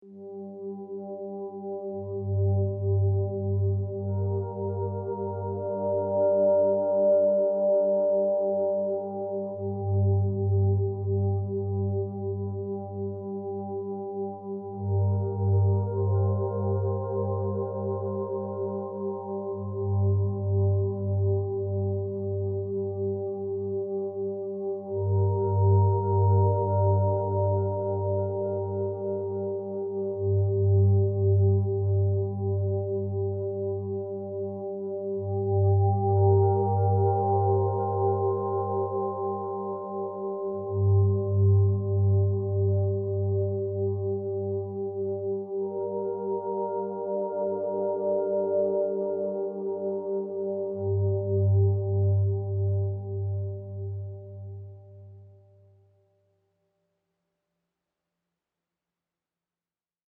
only pad